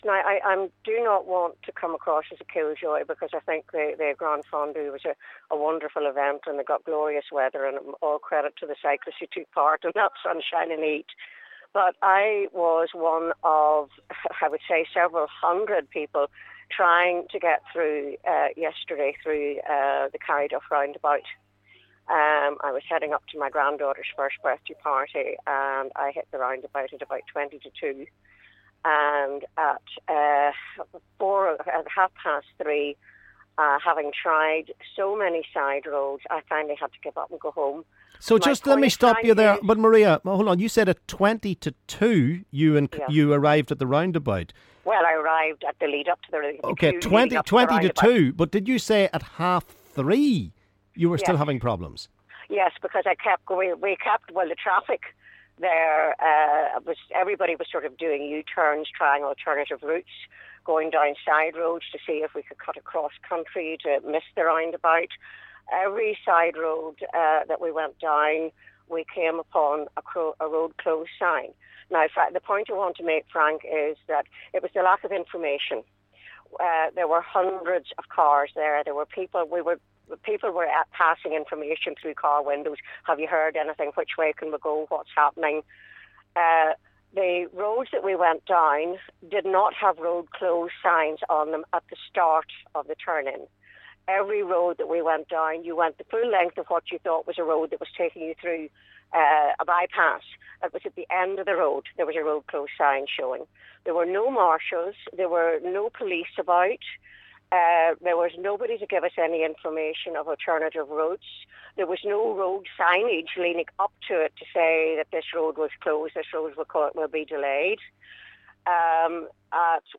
LISTEN: Callers have their say about the weekends Gran Fondo